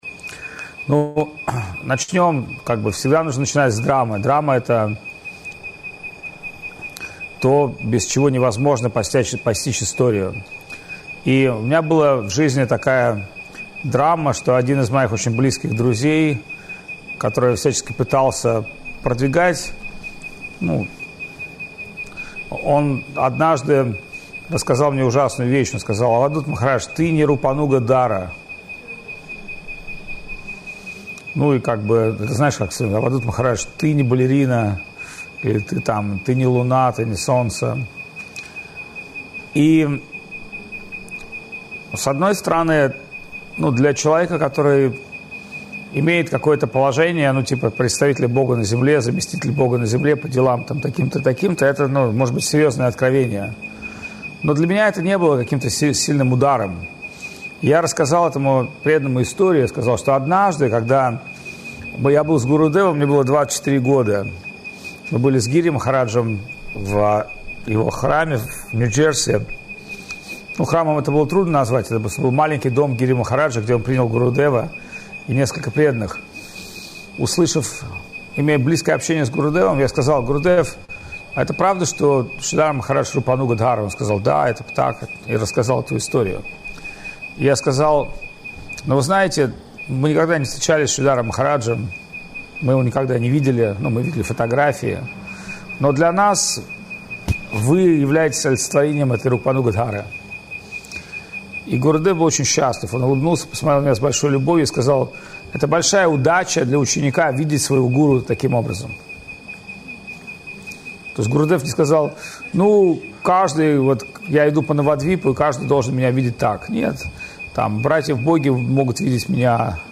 Чиангмай
Лекции полностью